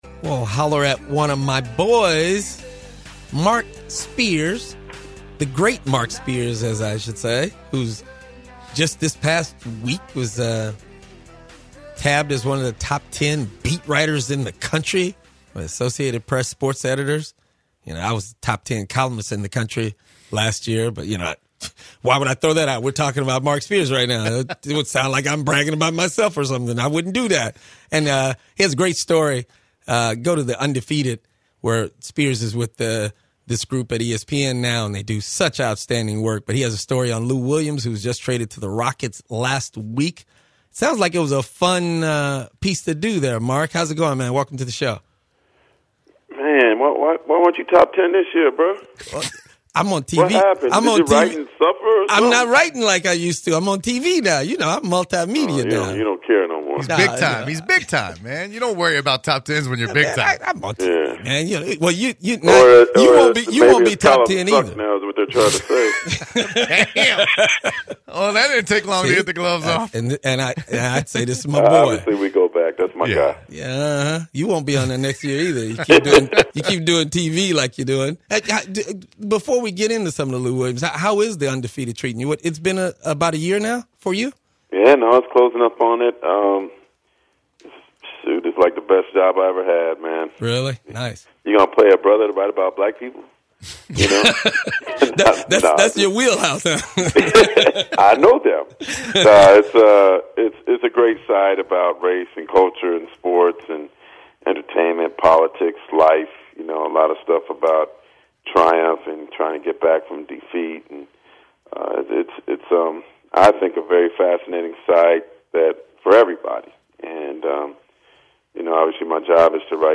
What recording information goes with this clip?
Acclaimed ESPN beat writer joins the program via phone to talk about his pick for MVP this year James Harden, he also talks about how well the Houston Rockets have done and their front office moves including the acquistion of Lou Willams.